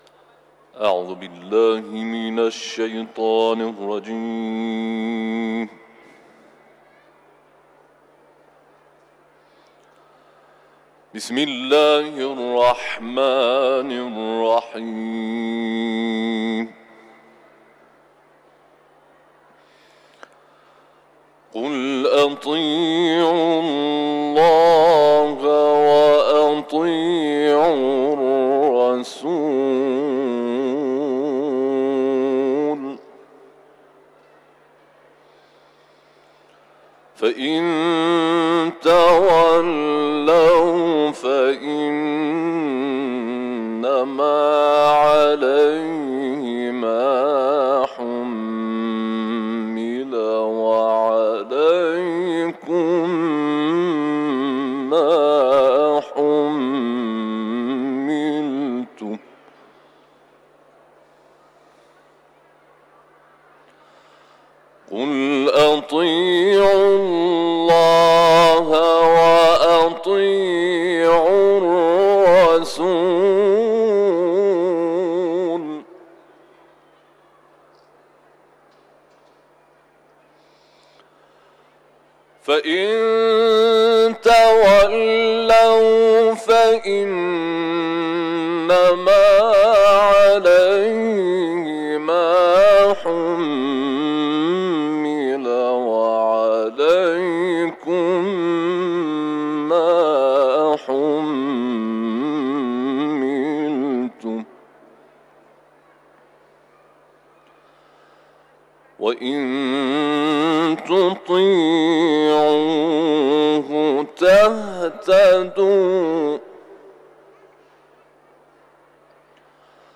در جوار بارگاه منور حضرت رضا(ع) تلاوت کرده است.
تلاوت